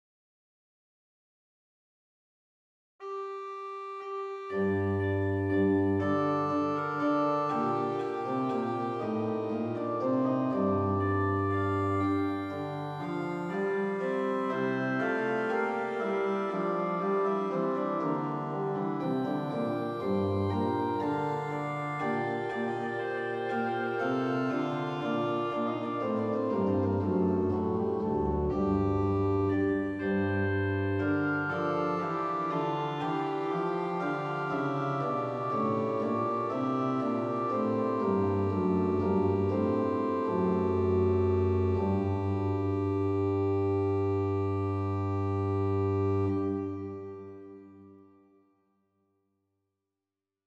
This is a setting of the traditional tune as an organ prelude.